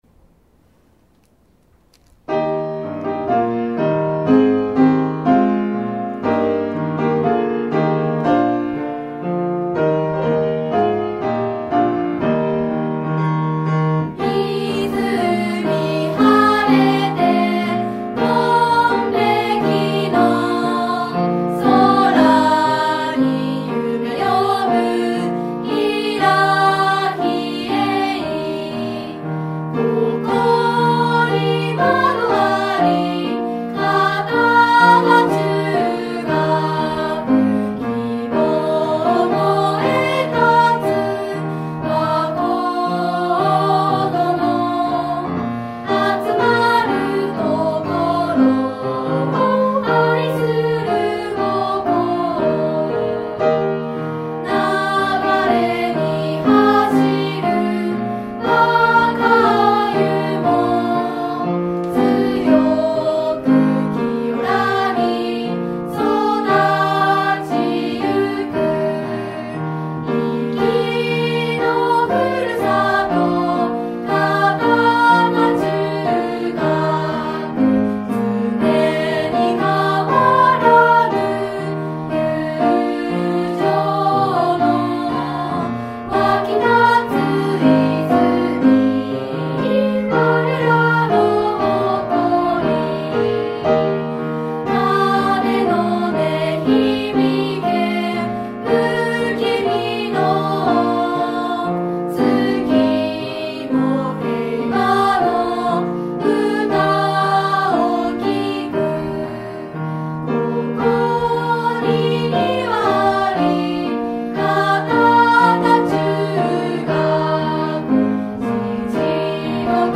校歌
在校生による校歌の録音データ（mp3形式）
女声